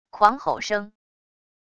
狂吼声wav音频